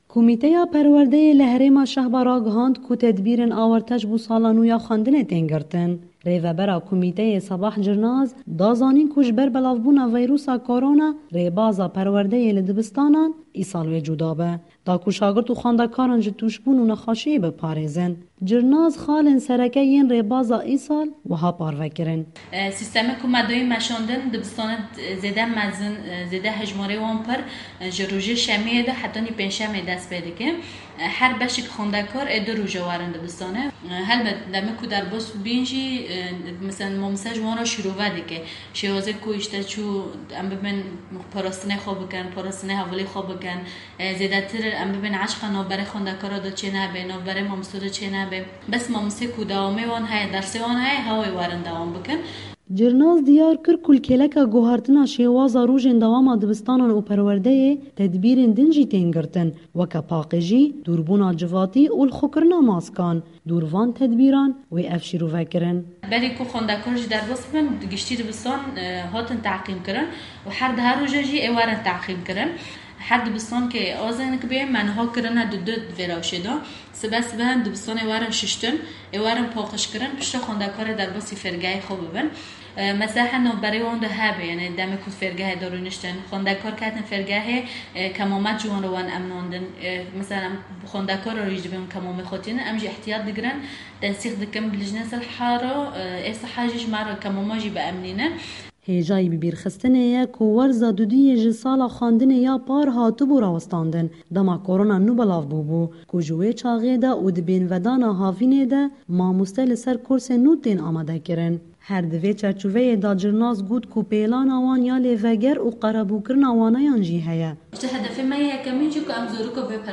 Raporta Efrînê